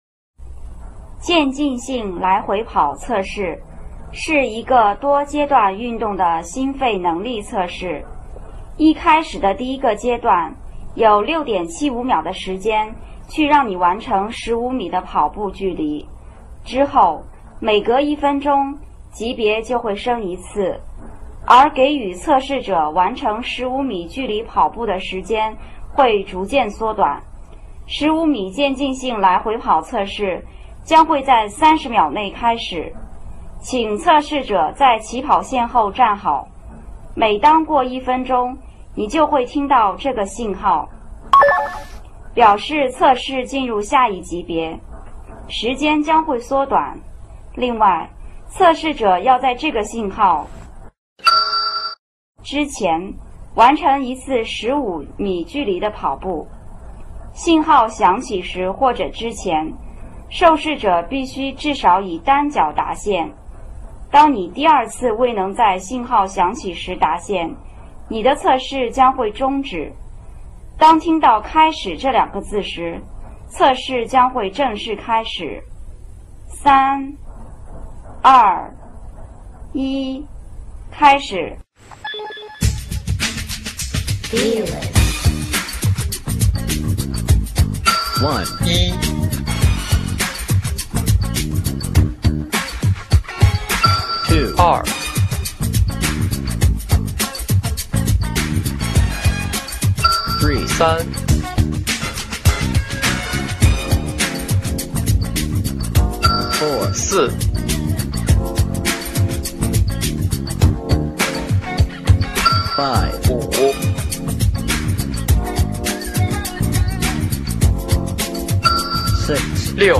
体育现场测试15米折返跑伴奏音乐.mp3
往返指令由音乐节奏控制，每次“叮”指令响起之前测试生至少要以单脚达线，听到“叮”的指令后测试生才开始下一次的折返跑，循环往复。